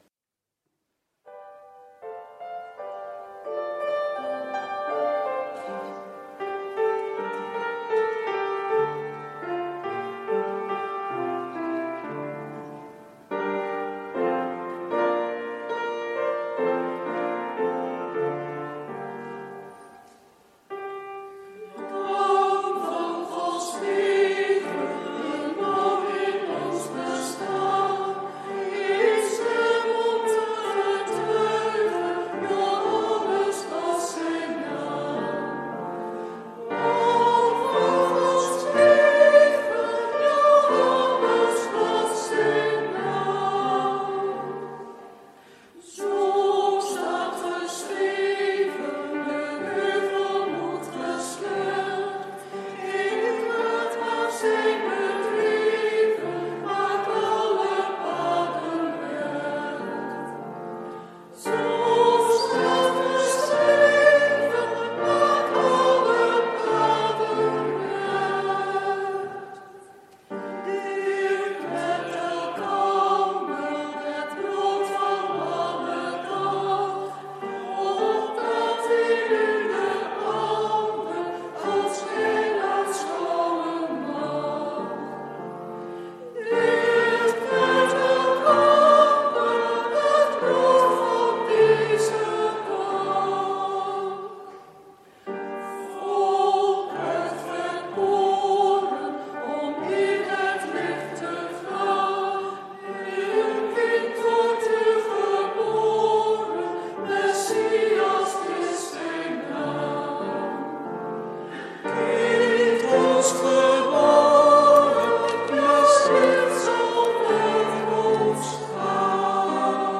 Eucharistieviering beluisteren vanuit De Goede Herder te Wassenaar (MP3)